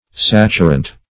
Saturant \Sat"u*rant\, n.